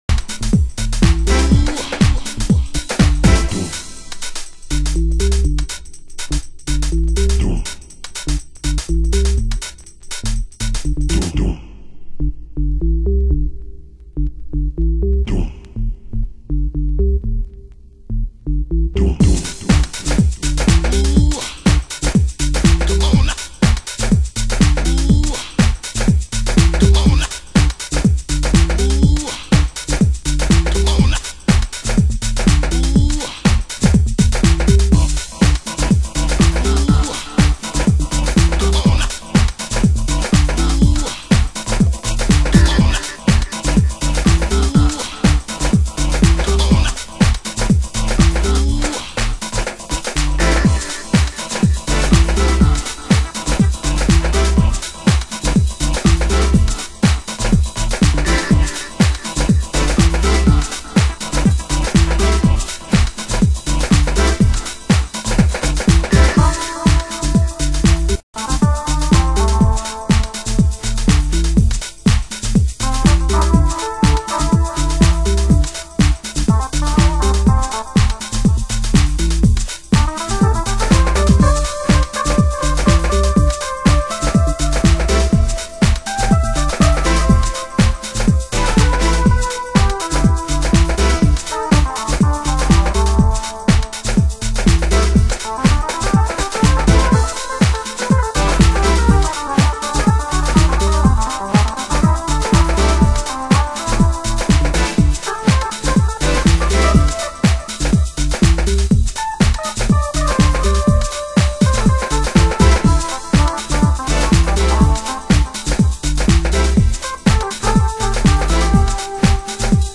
12" Vocal Mix